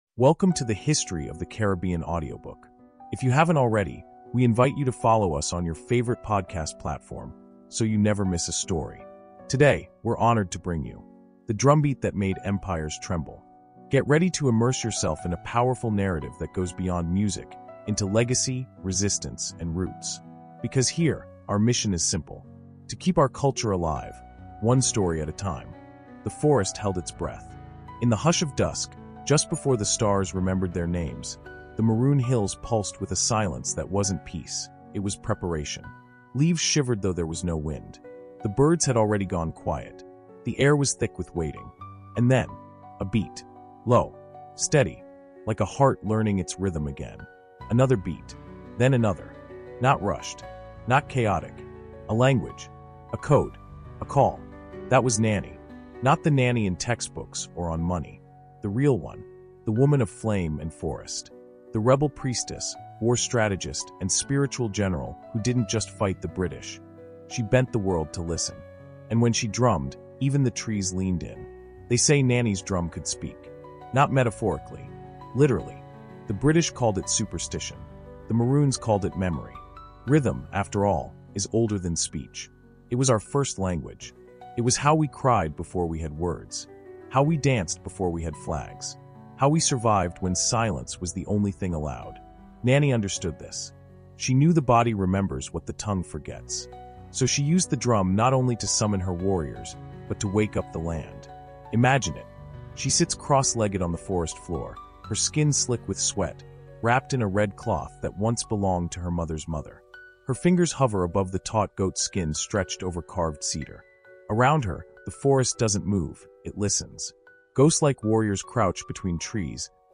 The Drumbeat That Made Empires Tremble | Audiobook Insight